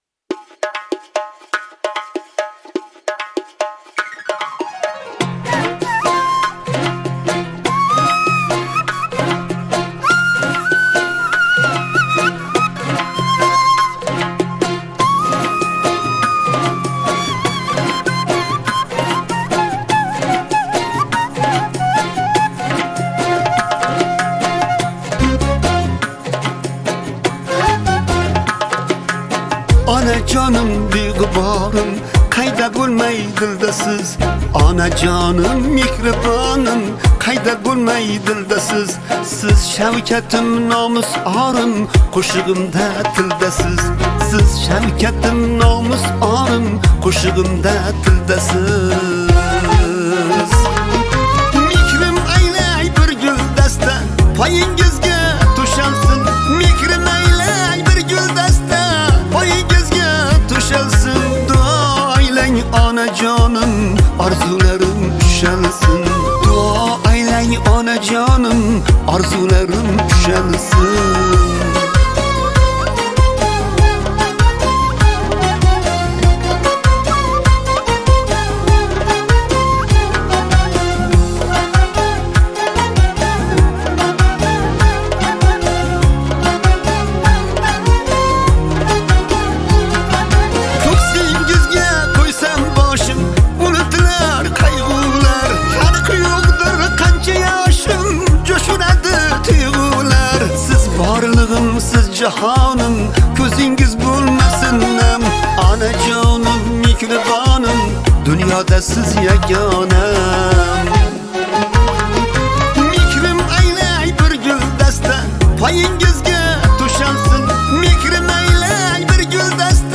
Узбекские песни